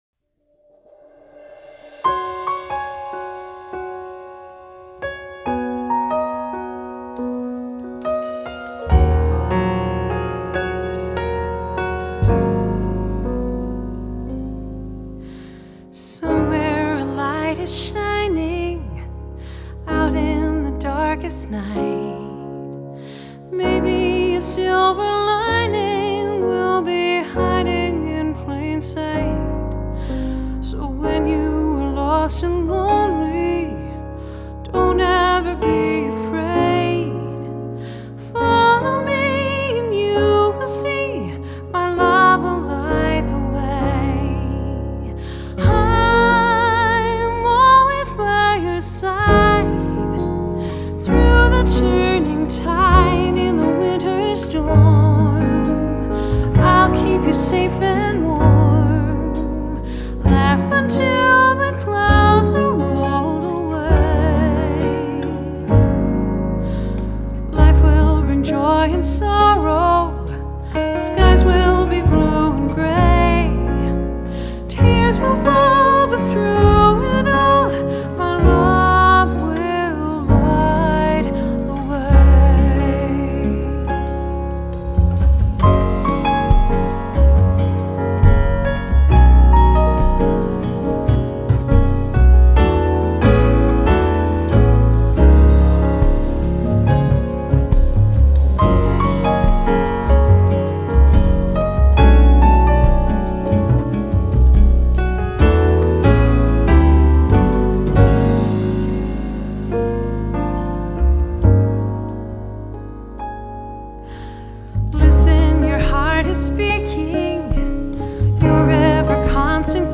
Genre: Vocal Jazz